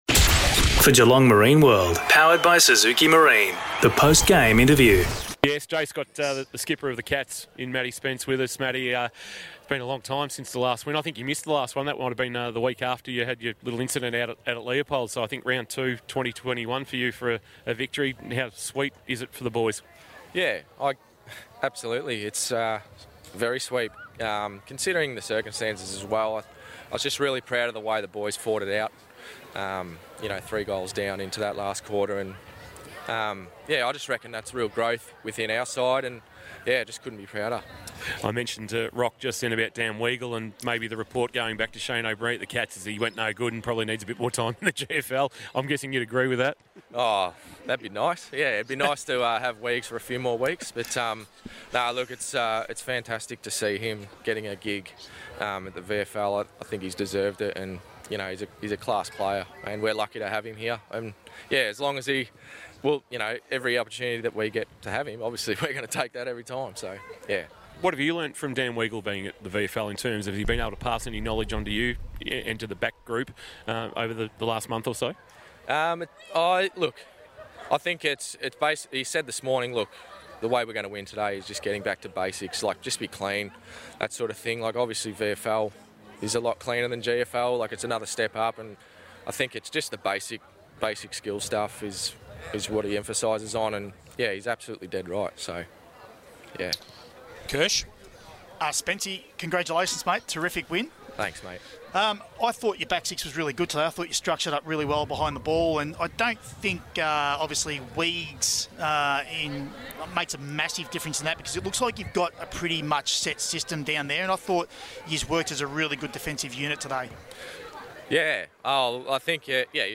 2022 - GFL - Round 10 - LARA vs. ST ALBANS: Post-match Interview